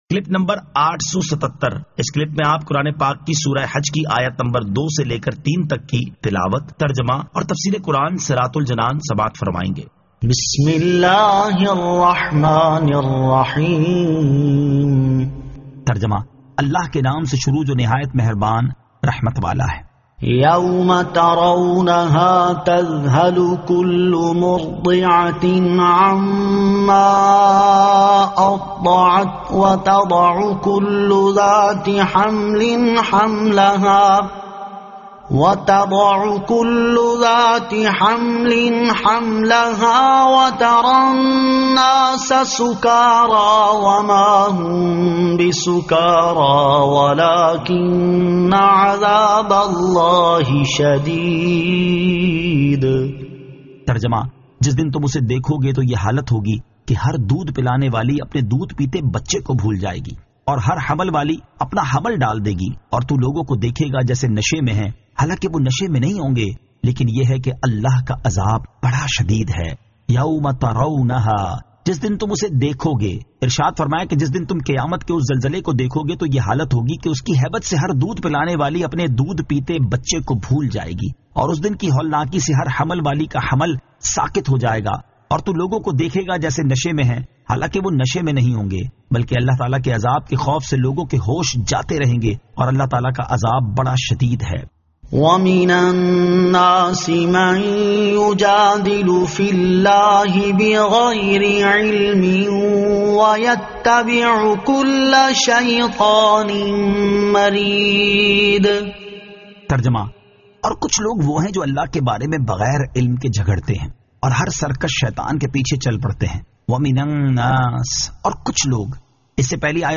Surah Al-Hajj 02 To 03 Tilawat , Tarjama , Tafseer